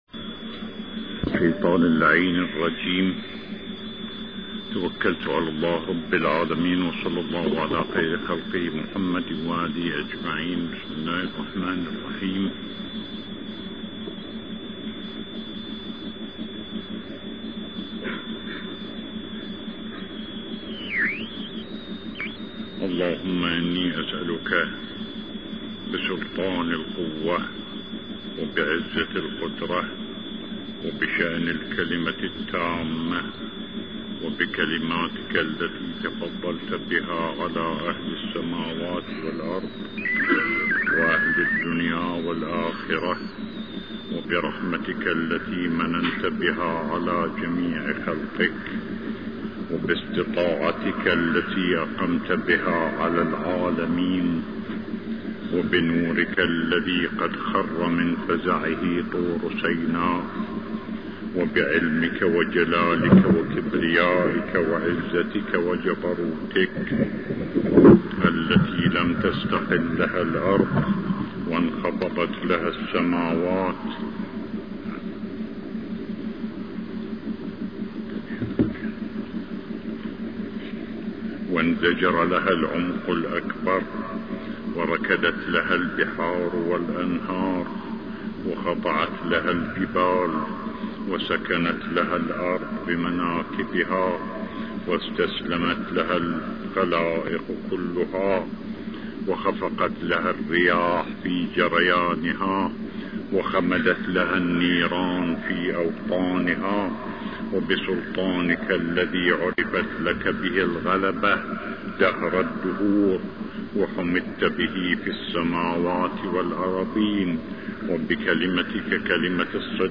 الجمعة السادسة ٢٥ محرم الحرام ١٤١٩ هـ الملف الصوتي الكامل لخطبتي صلاة الجمعة المباركة التي القاها سماحة السيد الشهيد اية الله العظمى محمد محمد صادق الصدر قدست نفسه الزكية في مسجد الكوفة المعظم ..